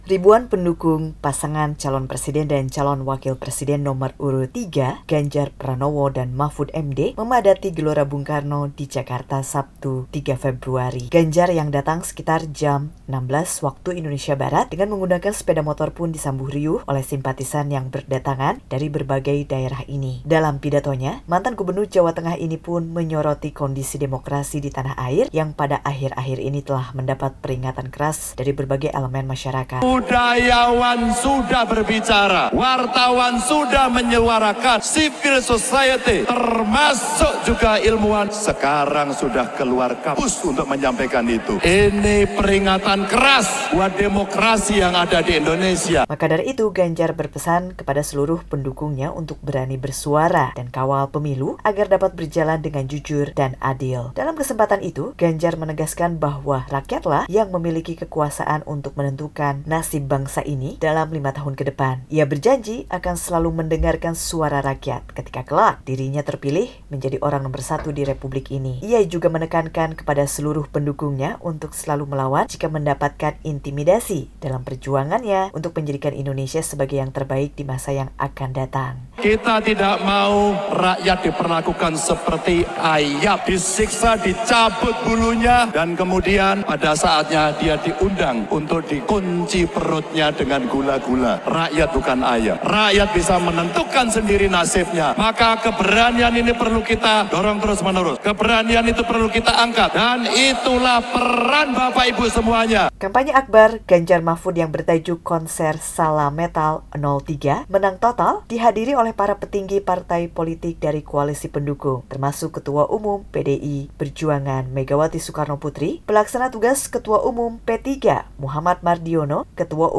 Ribuan pendukung pasangan calon presiden dan calon wakil presiden nomor urut 03 Ganjar Pranowo dan Mahfud MD memadati Gelora Bung Karno di Jakarta, Sabtu (3/2). Teriakan "Ganjar Presiden, Ganjar Presiden" pun menggema di sana.